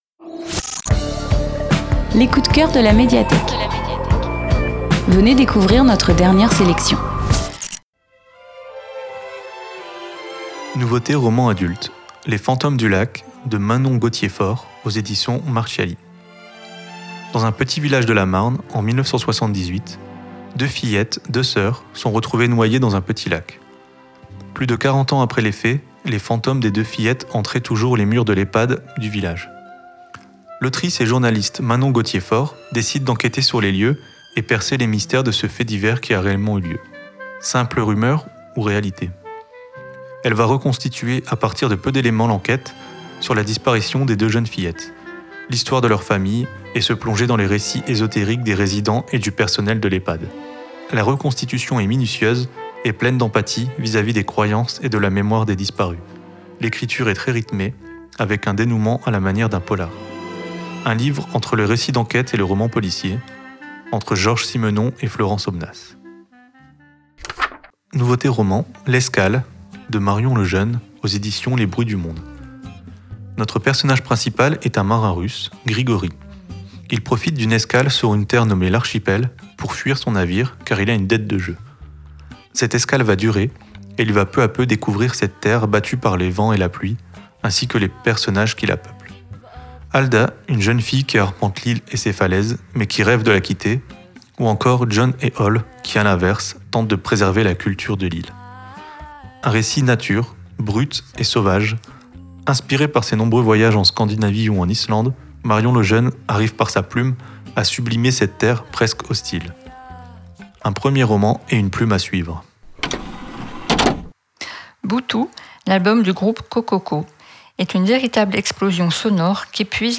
Tous les mardis à 15h00, l’équipe de la Médiathèque de Villebon vous présente ses quelques coups de cœurs : livres, DVD, événements…